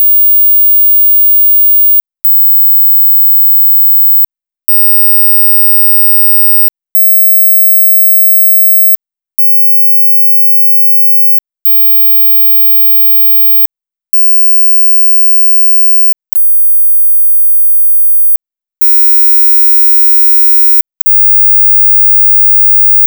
Listen to 15,500-20,000hz tones in increments of 500hz and pauses for 1,000hz intervals: